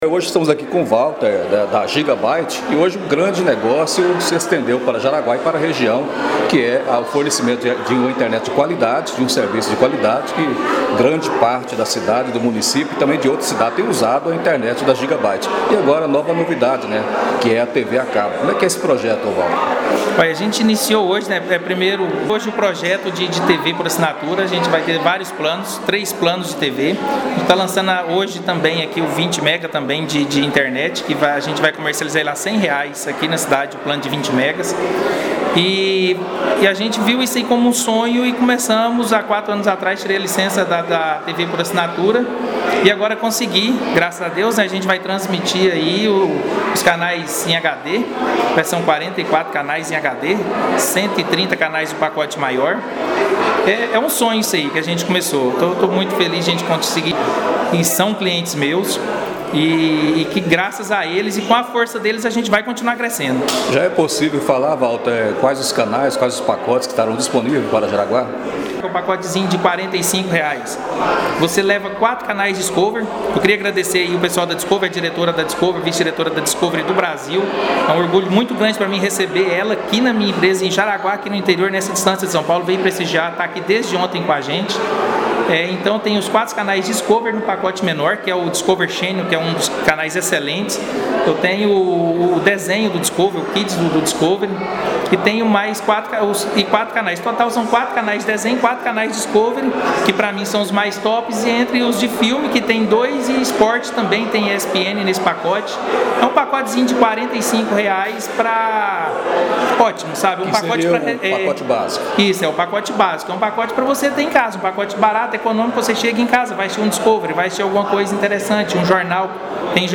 O lançamento inaugural da Giga Byte HD TV aconteceu na manhã desta quinta-feira, na sede administrativa da empresa, localizada no Setor Primavera. Estiverem presentes convidados e clientes da Giga Byte, além de autoridades civis, militares e eclesiásticas.